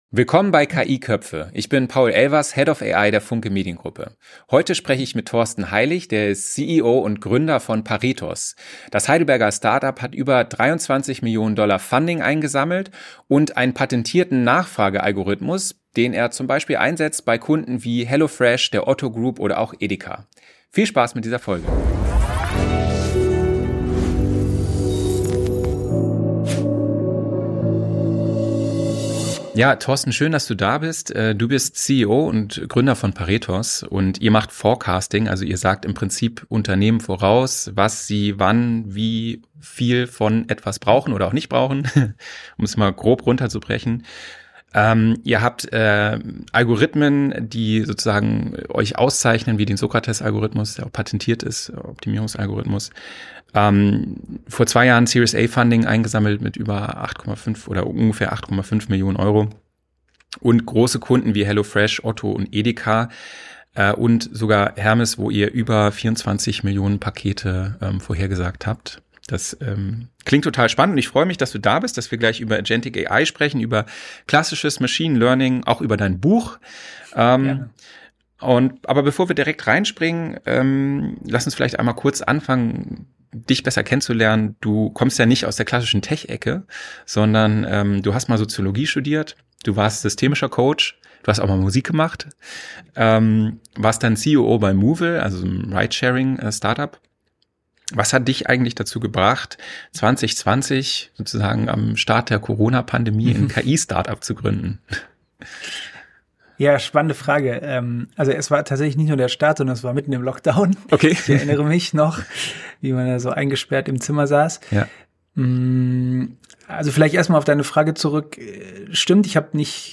Ein Gespräch über Mut zur Anwendung, KI als neuen Mitarbeitenden und die Zukunft europäischer Tech-Innovation.